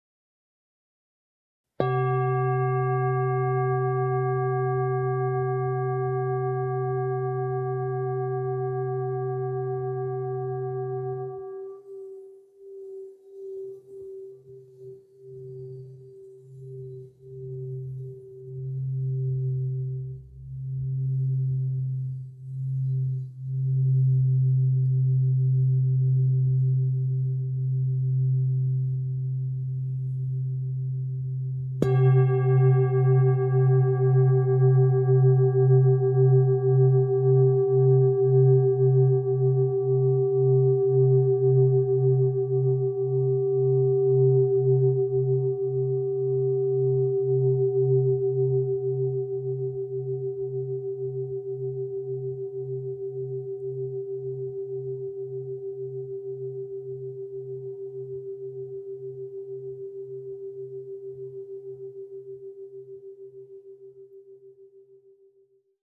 Meinl Sonic Energy Cosmos Series Singing Bowl - 1500g (SB-C-1500)
Durch sanftes Anschlagen oder Reiben erzeugen die Sonic Energy Klangschalen einen faszinierenden, vielschichtigen und farbenreichen Klang, der tief in die Seele hineinklingt. Über einem satten Grundton entstehen ganze Kaskaden von singenden Obertönen, die frei im Raum schweben und sich im Körper entfalten. Sobald der Klang zu schwingen beginnt, hört er nicht mehr auf; selbst nach einer Minute ist noch ein sanfter Nachhall zu spüren.